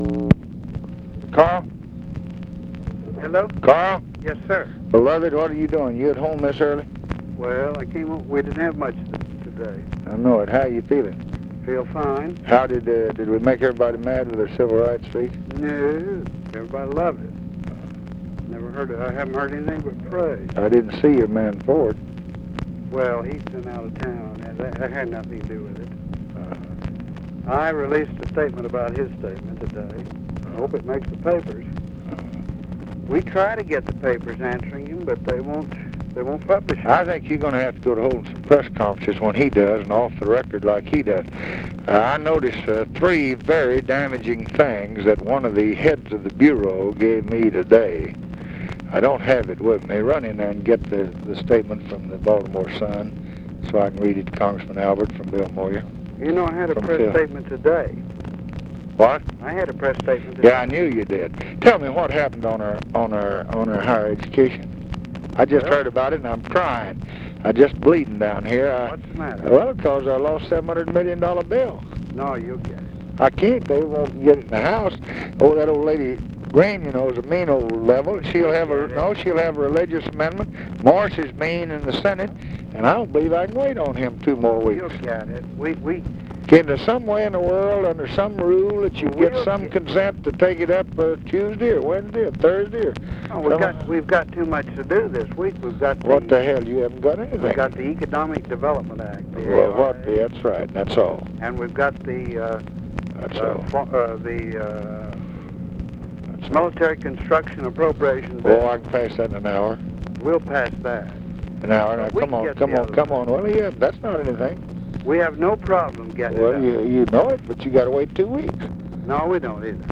Conversation with CARL ALBERT, August 6, 1965
Secret White House Tapes